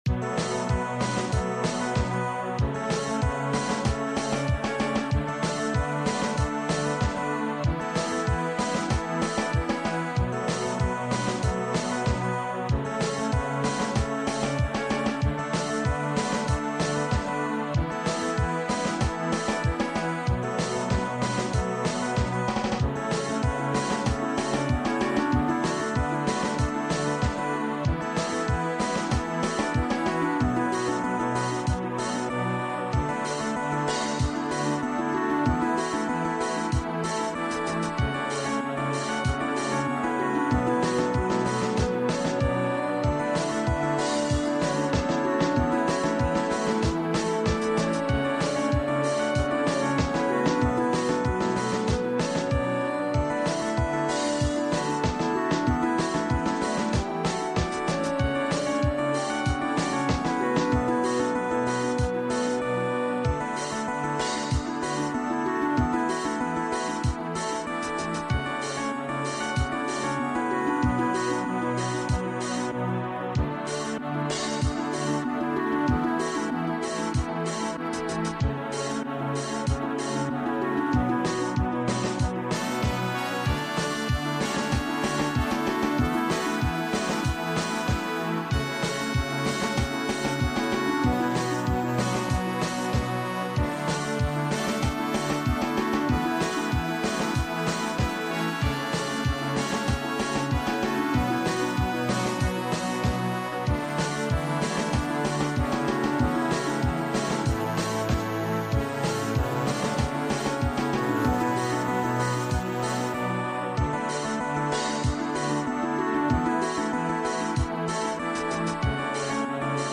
Application of EBU R 128 to all BGM